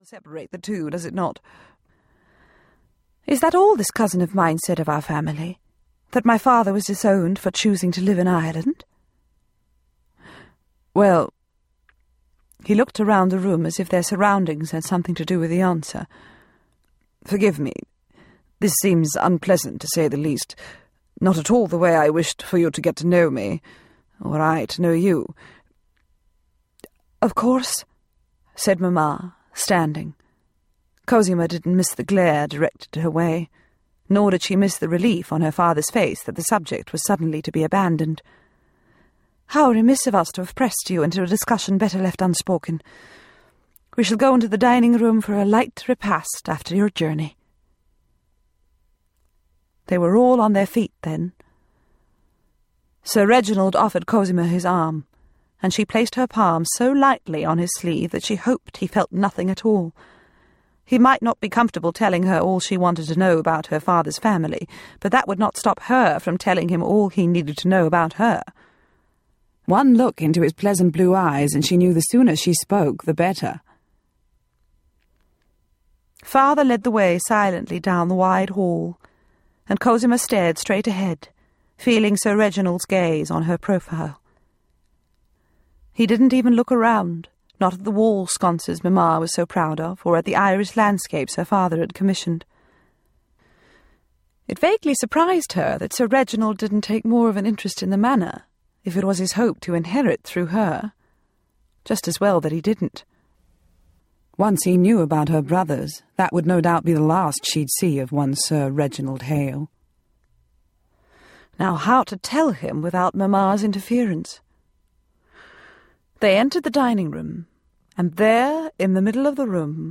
The Oak Leaves Audiobook